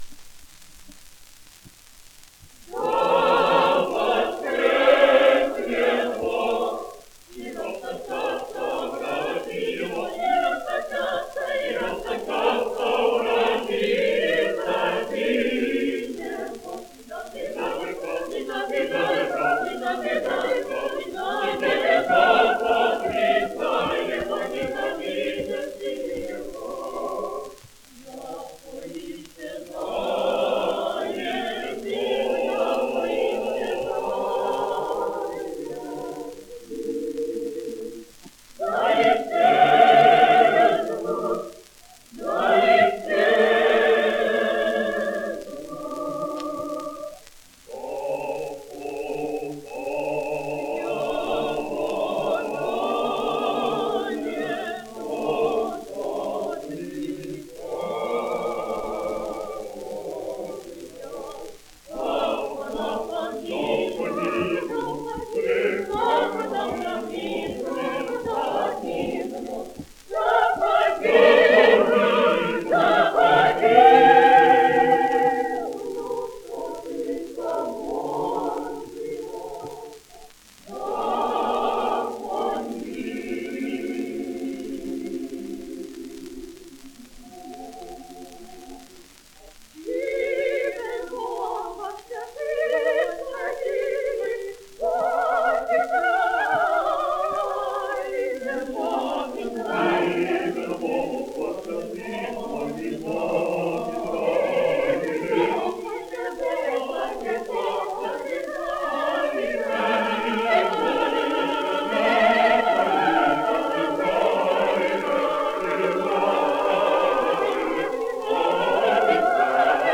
Prior to the First World War, and the Russian Revolution, the only place to hear Russian choral singing in America was in the Russian Orthodox Churches, which were built by Russian immigrants between 1880 and 1910.
Russian Orthodox Cathedral Choir of Paris